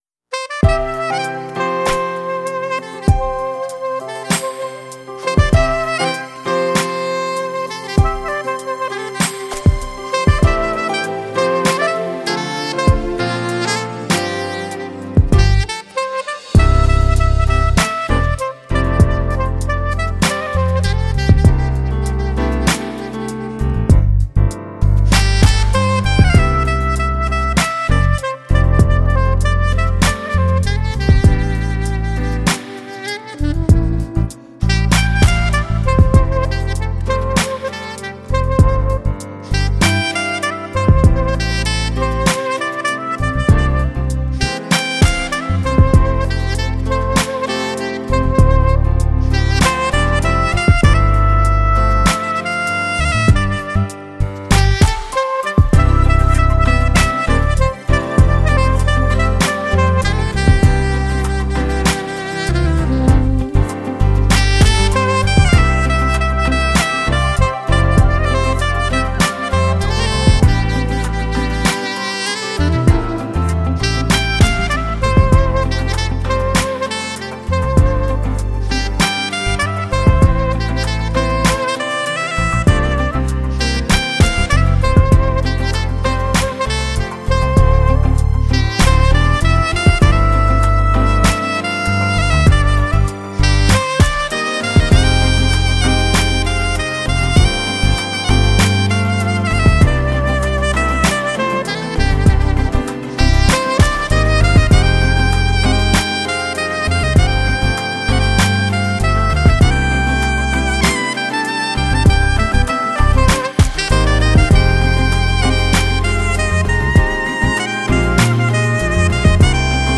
ساکسفون
Saxofon-Sunrise.mp3